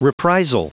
Prononciation du mot reprisal en anglais (fichier audio)
reprisal.wav